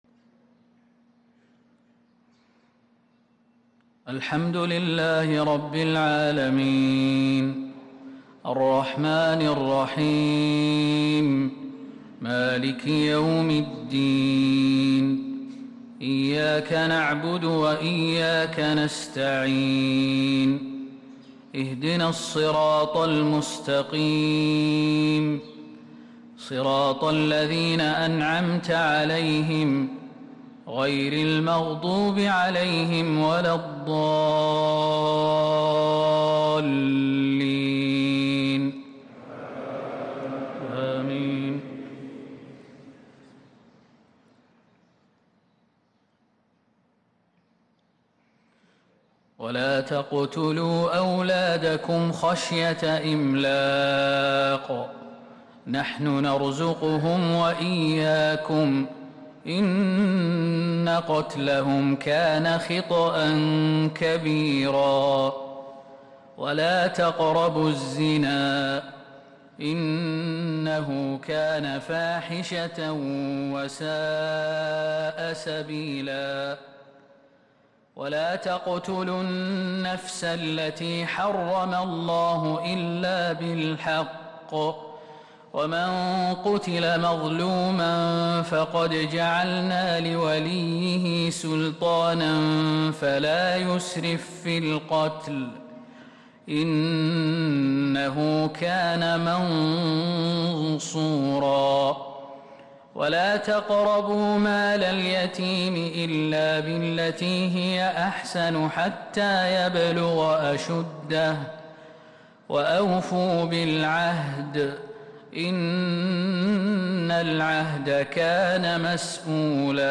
فجر السبت ٣ ذو الحجة ١٤٤٣هـ من سورة الإسراء | Fajr prayer from Surat Al-Israa 2-7-2022 > 1443 🕌 > الفروض - تلاوات الحرمين